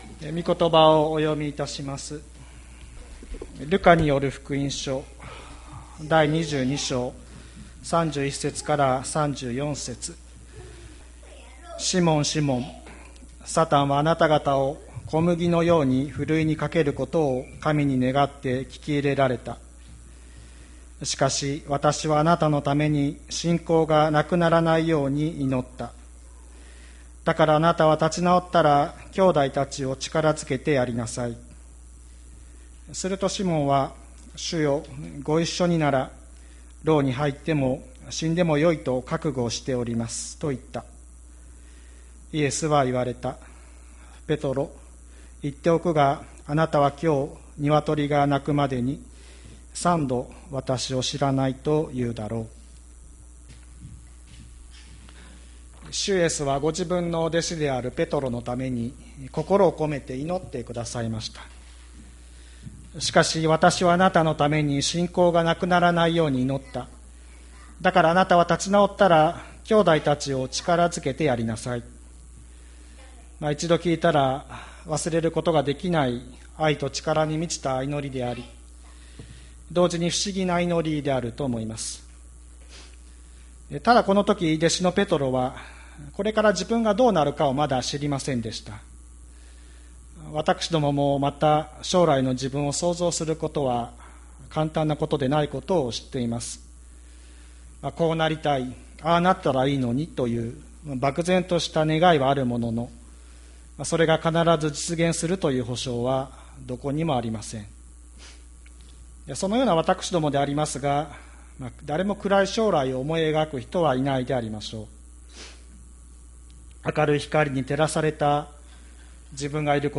千里山教会 2020年07月12日の礼拝メッセージ。